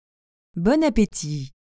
The correct French pronunciation is: bohn ah-pay-tee or [bɔn-apeti].